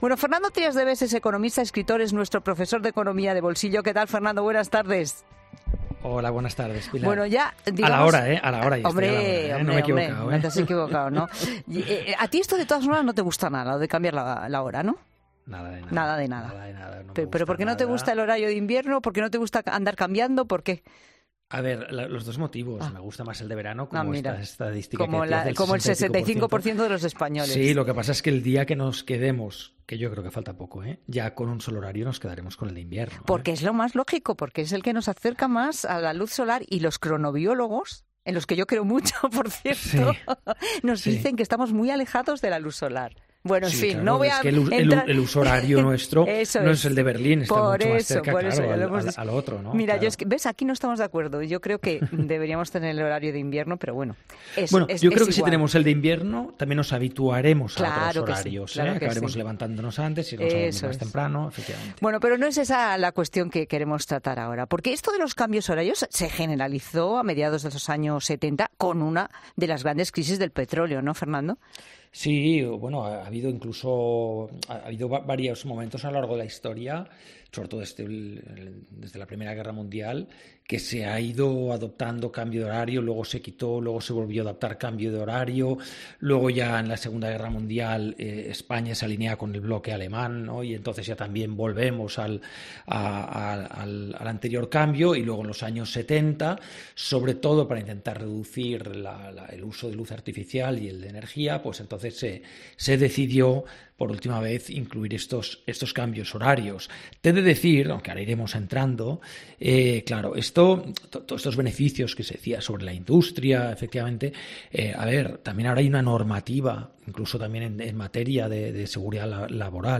Fernando Trías de Bes detalla en La Tarde si realmente compensa un cambio de horario a nivel económico: ¿de verdad ahorramos?
Fernando Trías de Bes explica este lunes en La Tarde de COPE si realmente compensa, teniendo en cuenta todos los factores, que alteremos nuestros biorritmos por un bien monetario superior. Además, explica el economista a Pilar Cisneros qué es la 'desincrosis del sueño' y cómo puede terminar haciéndote perder dinero.